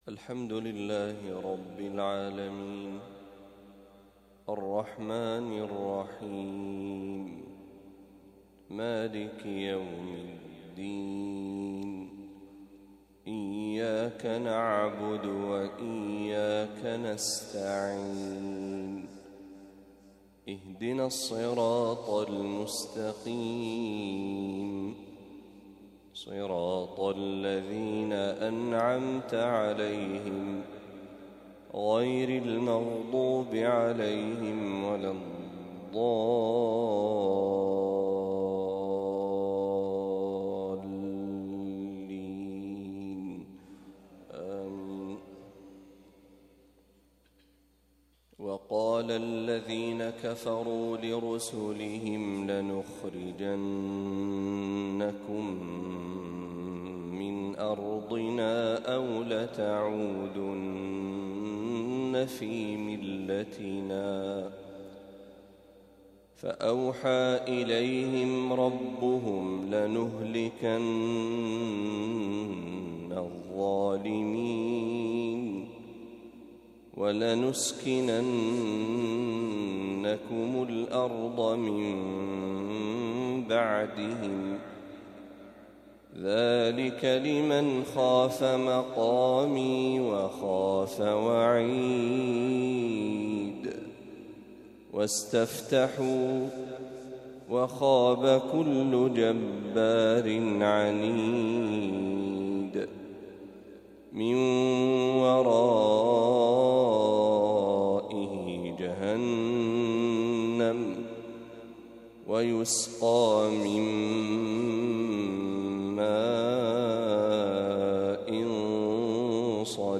ما تيسر من سورة إبراهيم | فجر الأربعاء ٣ صفر ١٤٤٦هـ > 1446هـ > تلاوات الشيخ محمد برهجي > المزيد - تلاوات الحرمين